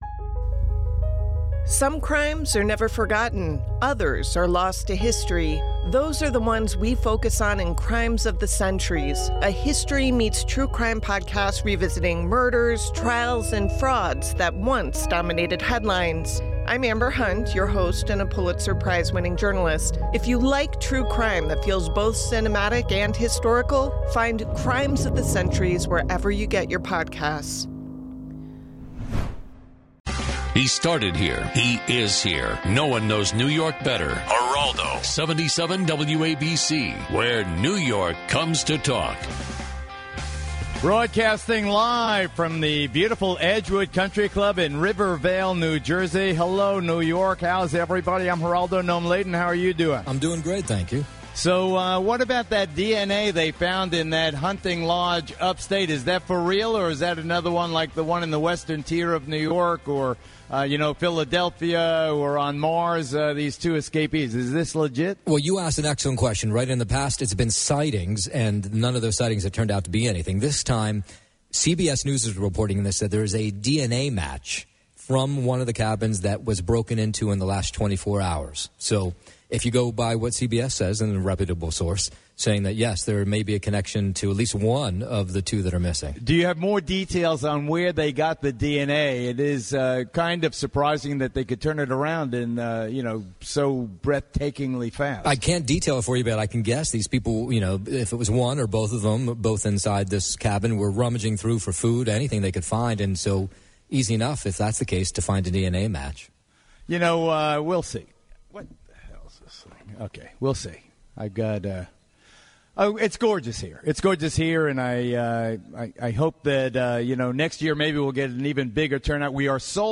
Geraldo broadcasts live before the annual Geraldo Golf Classic!